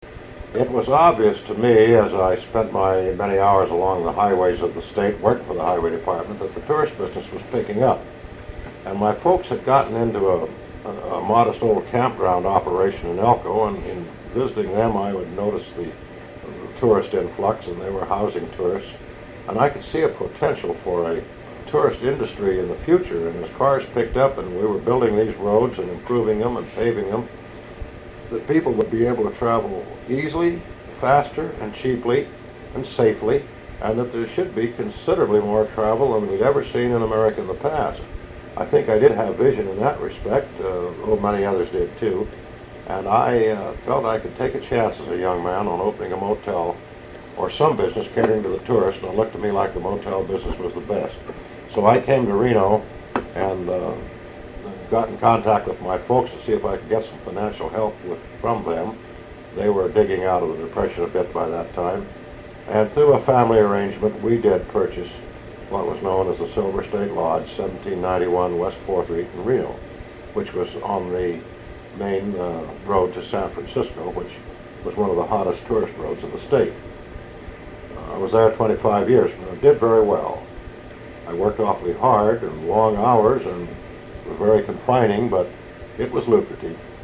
Interviewed in 1977
Oral history